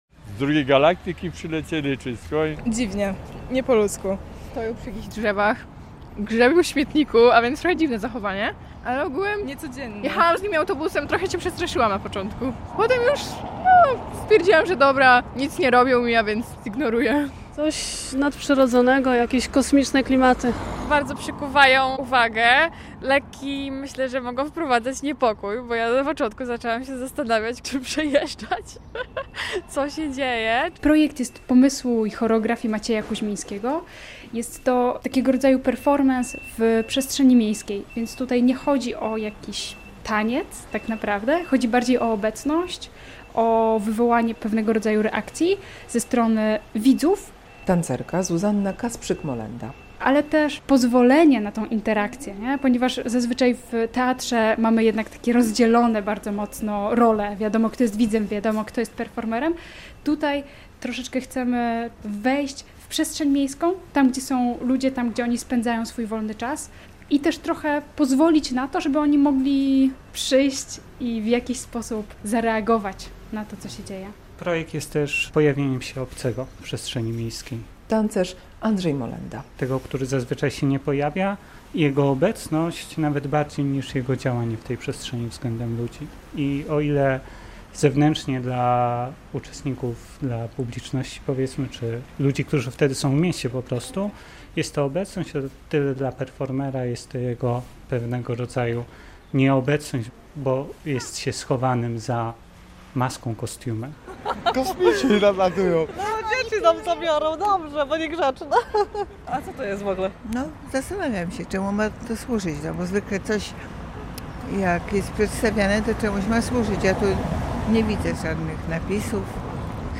Projekt "Krótkotrwałość" na festiwalu Kalejdoskop - relacja